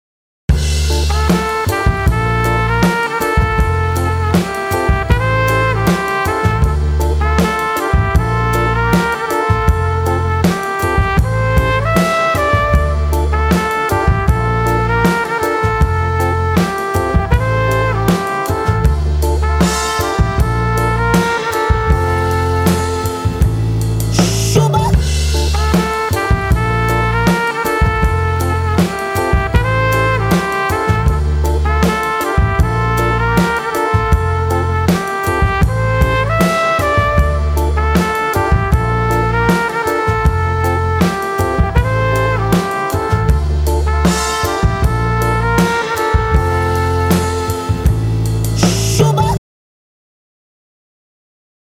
• Качество: 320, Stereo
без слов
инструментальные
оркестр